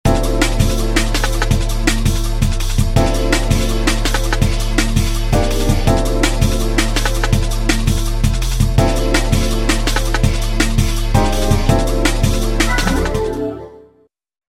This is the C215 Mercedes Benz sound effects free download